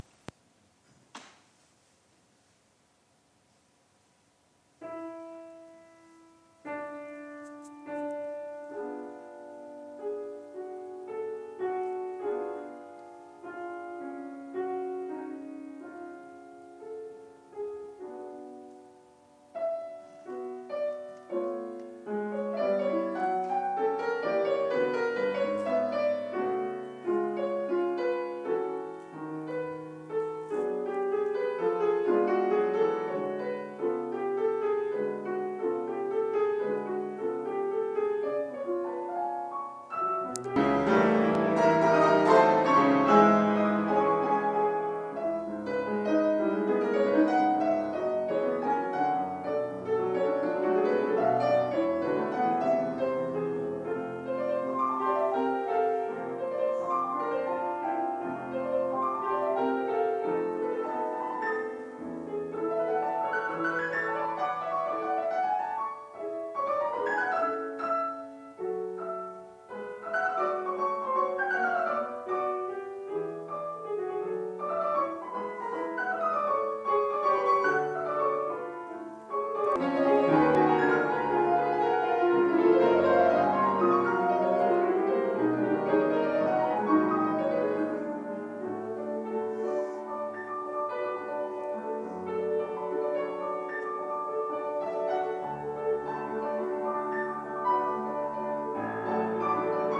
kaisersaal klavierkonzert 5